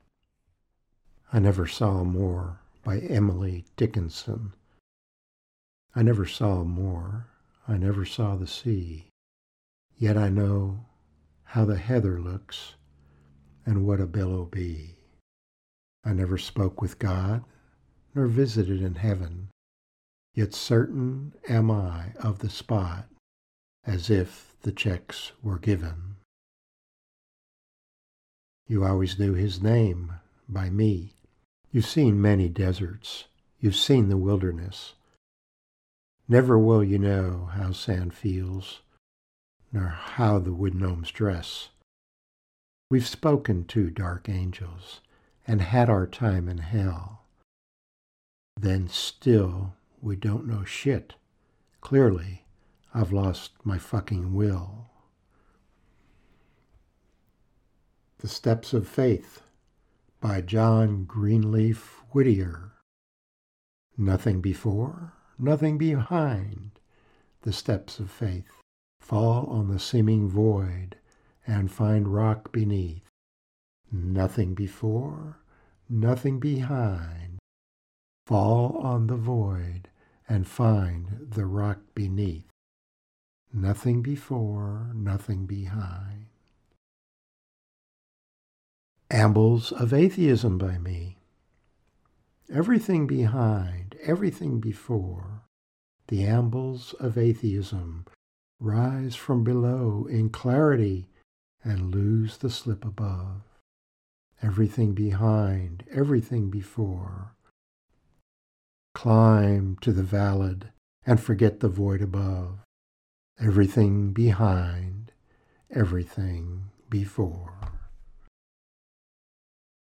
My voiceover is all four poems.